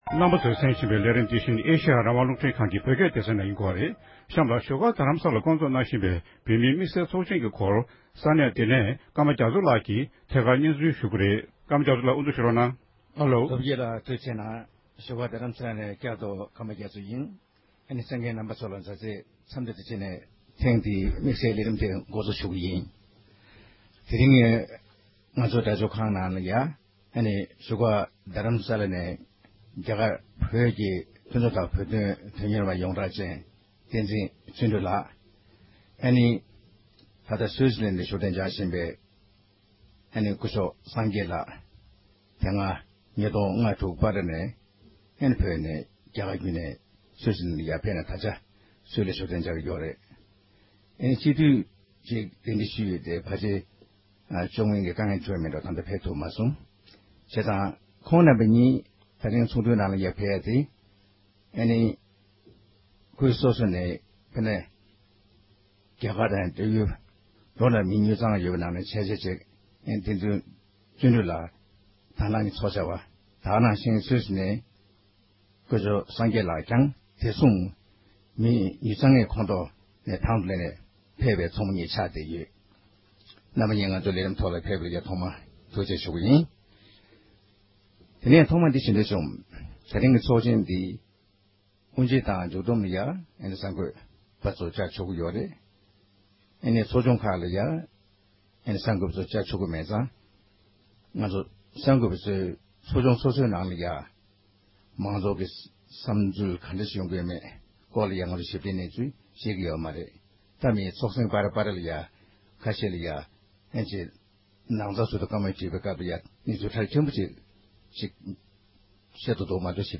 གླེང་མོ་ཞུས་པ།